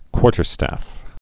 (kwôrtər-stăf)